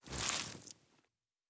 added stepping sounds
Wet_Snow_Mono_03.wav